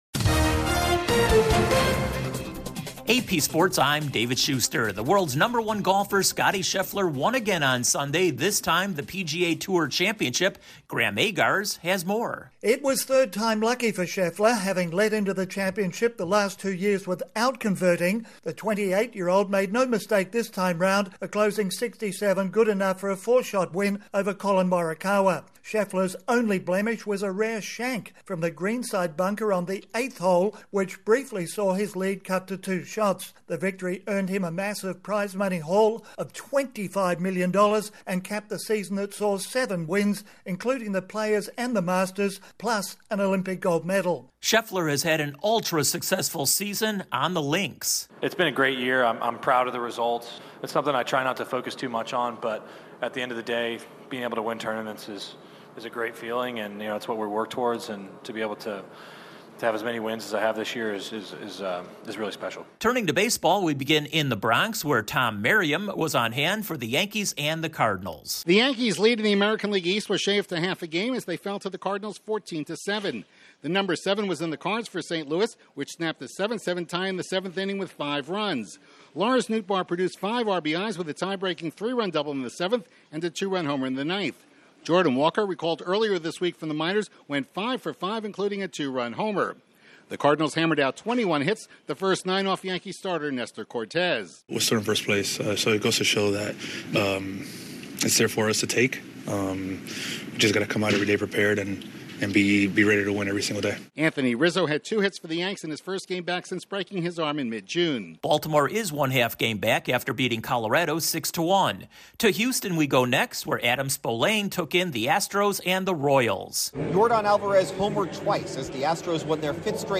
The latest in sports